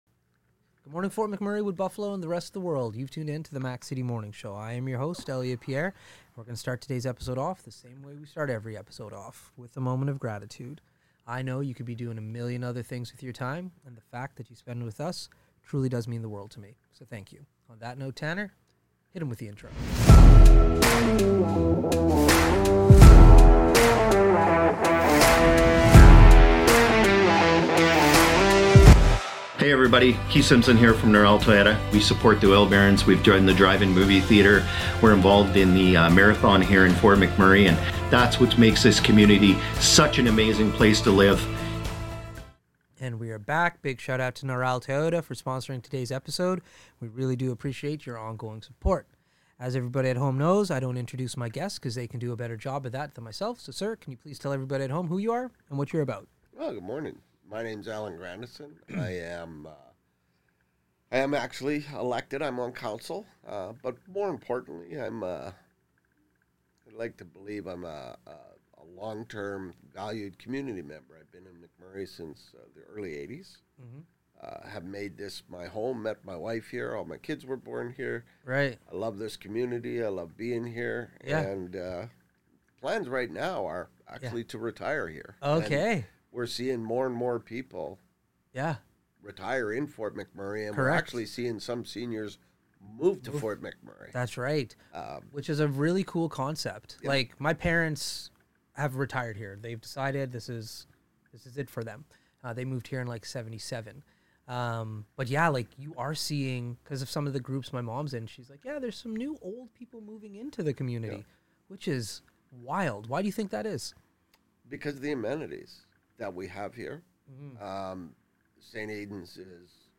On the show today, we have Alan Grandison a Local Councilor and long term Fort McMurray resident!